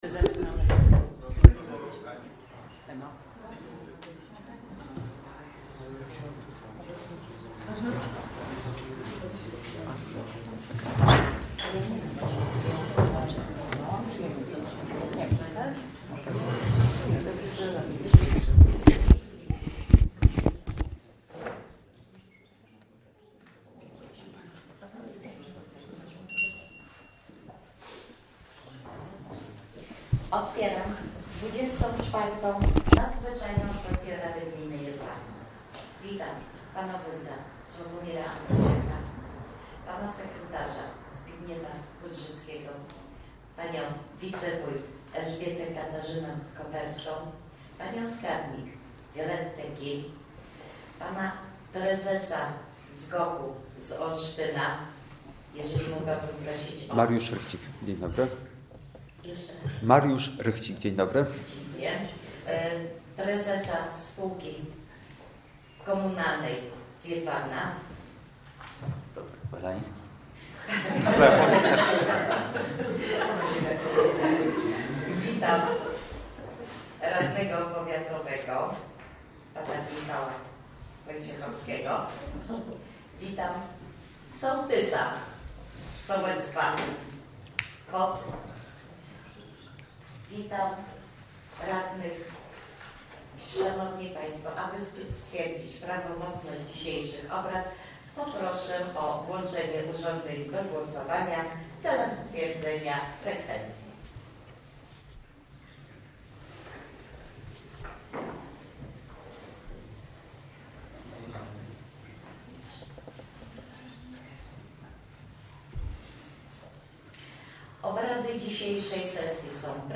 Nagrania audio z sesji Rady Gminy Jedwabno kadencja IX 2024-2029